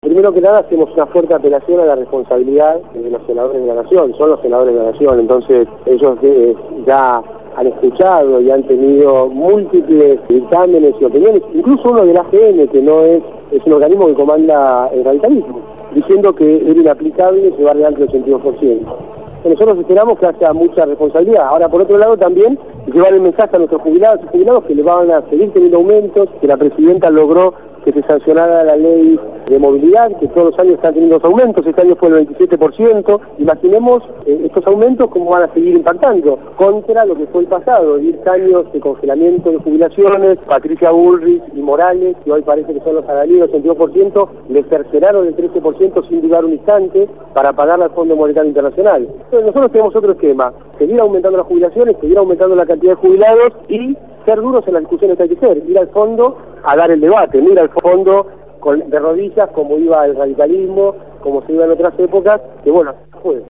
Amado Boudou, Ministro de Economía de la Nación, fue entrevistado